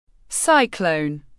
Cyclone /ˈsaɪ.kləʊn/